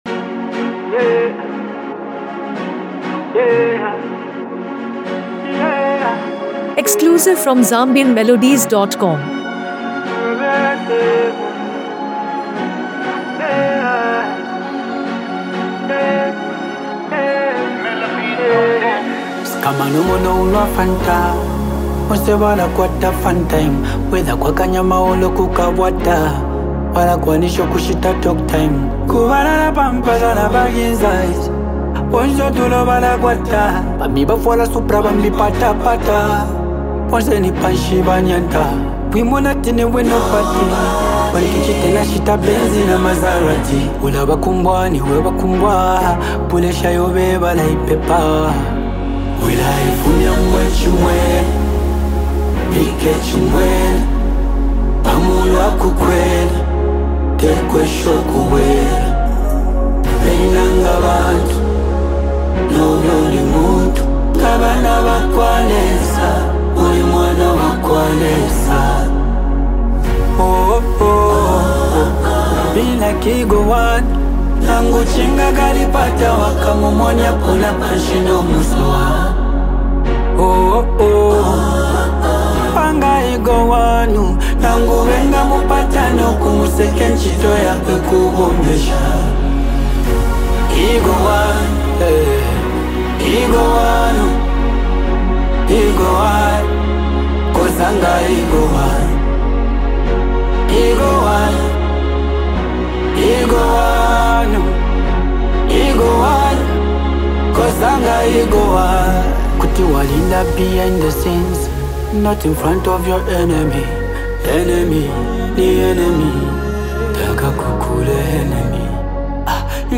Zambian Music
With powerful lyrics and emotionally driven delivery